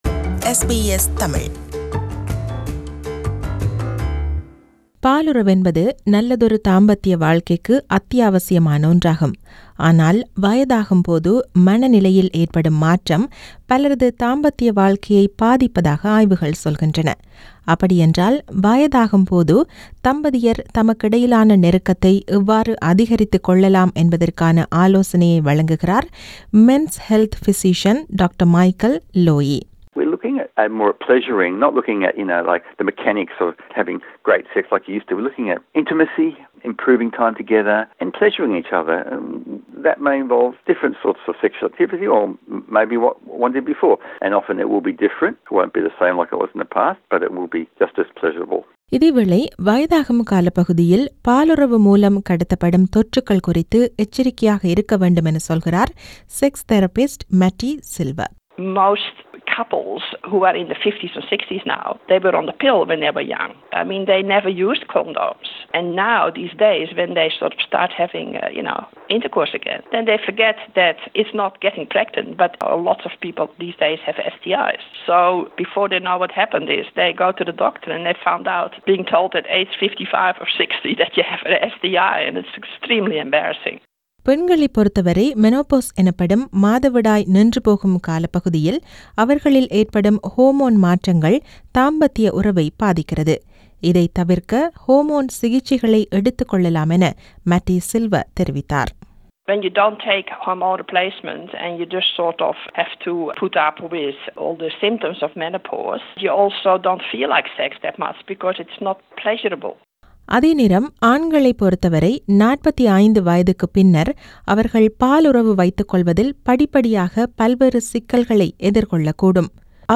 வயதாகும் போது மனநிலையில் ஏற்படும் மாற்றம் பலரது தாம்பத்திய வாழ்க்கையை பாதிப்பதாக ஆய்வுகள் சொல்கின்றன. அப்படியென்றால் வயதாகும் போது தம்பதியர் தமக்கிடையிலான நெருக்கத்தை எவ்வாறு அதிகரித்து கொள்ளலாம்? இது குறித்த விவரணம்.